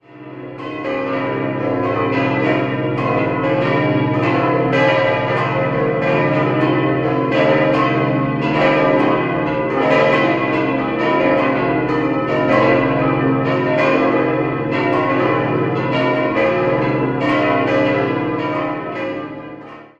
6-stimmiges ausgefülltes und erweitertes B-Moll-Geläute: b°-des'-es'-f'-as'-b' Alle Glocken wurden im Jahr 1948 vom Bochumer Verein für Gussstahlfabrikation in der so genannten "Sekundschlagrippe" gegossen.